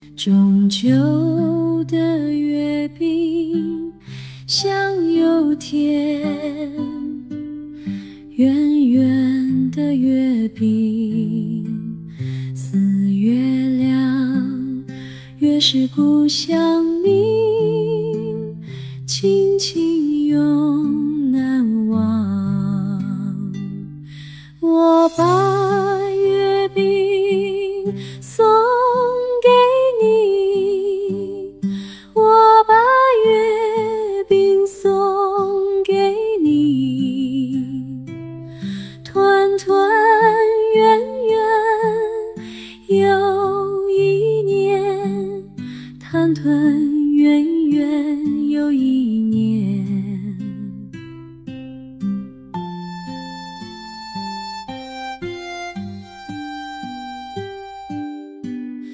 AI音乐遇上中秋
遇到中秋节，它又能碰撞出怎样的火花呢？“豆包，我想要这么一首歌曲，它的风格是民谣，氛围很放松，要女声，歌词主题是中秋节团圆”，让我们看看它的效果。